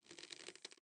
Creak 2.wav